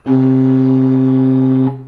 Dulciana16 - Analysis / Features / Modeling
(WAVE mono 16-bit 48 kHz)
dulciana16.wav